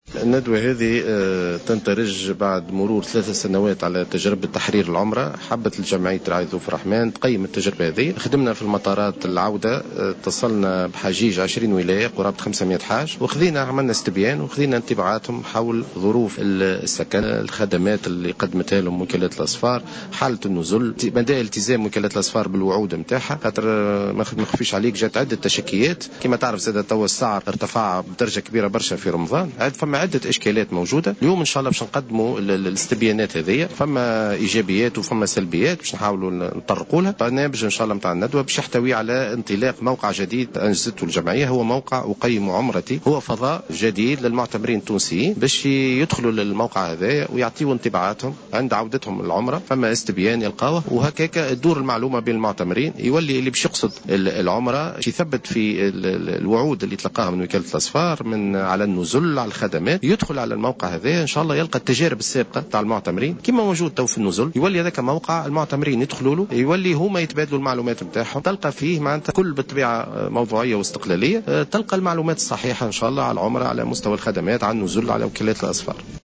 في تصريح لمراسل جوهرة أف أم